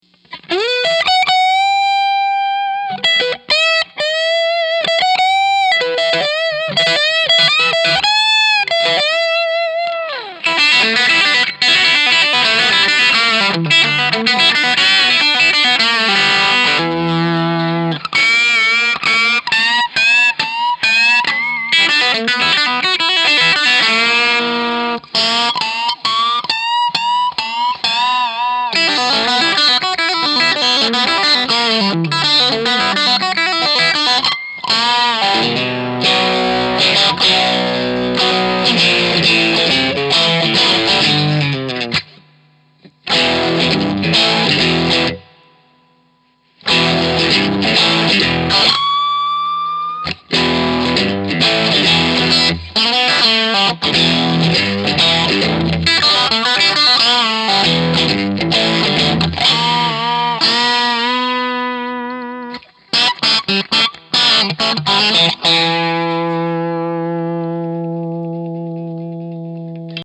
We used a Two Rock 112, Open back cabinet with a Tone Tubby Alnico 16:
Strat
Strat_Dirty_Chan_SemiDirt
ODS_Strat_Dirty_Chan_SemiDirt.mp3